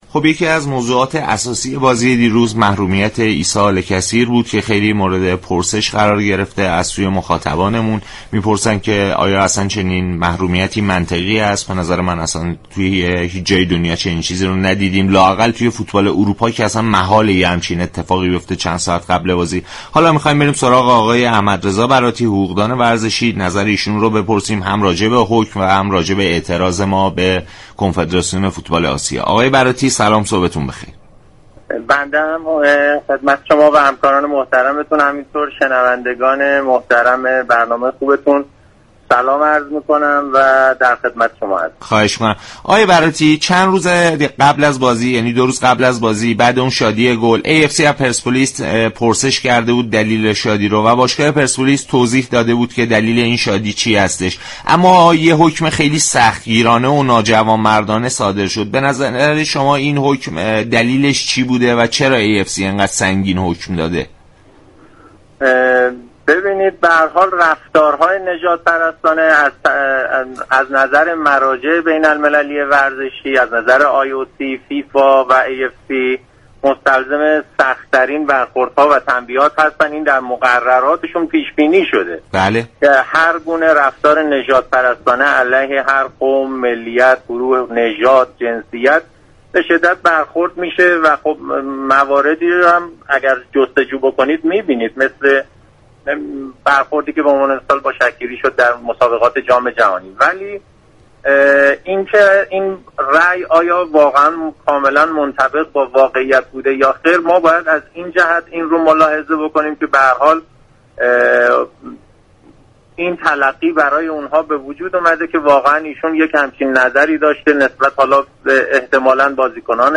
شما می توانید از طریق فایل صوتی پیوست بطور كامل شنونده این گفتگو باشید.